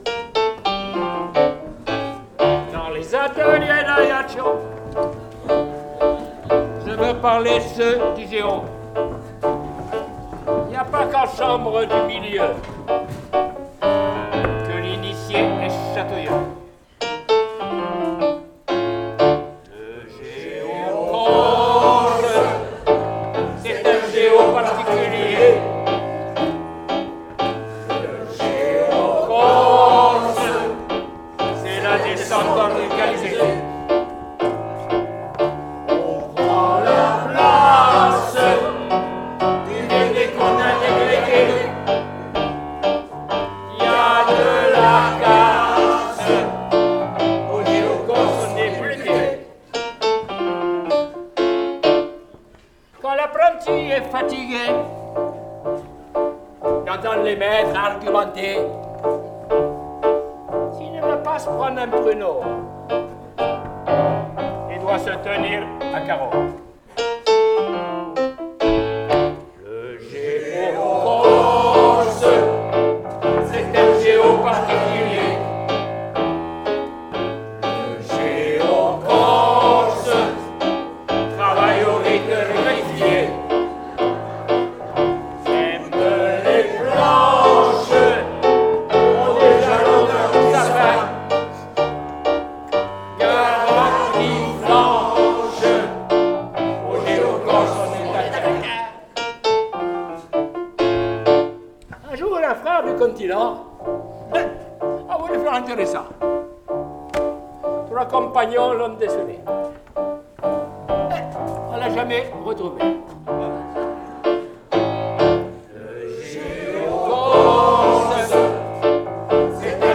Enregistrement public pendant le sketch
Dernier enregistrement public: Festival 2025